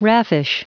Prononciation du mot raffish en anglais (fichier audio)
Prononciation du mot : raffish